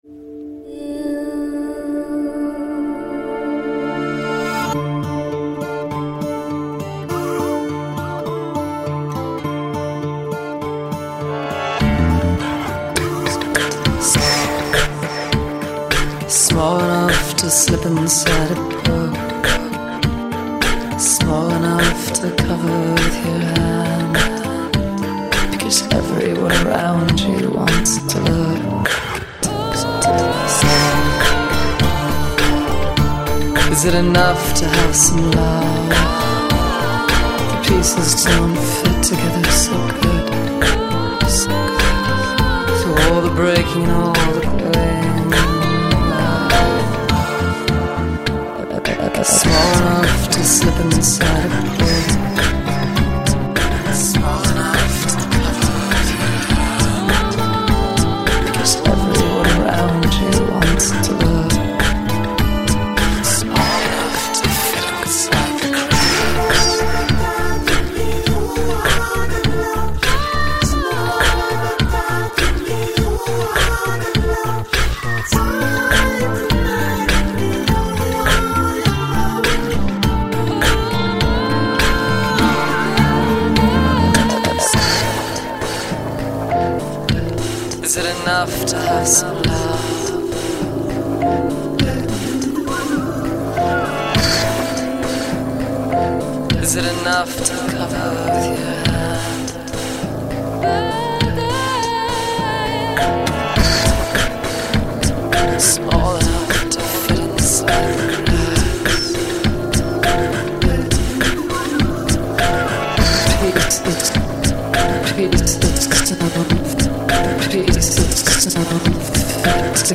Chosen for the very creative use of all samples and stems, nice harmonization, and production value.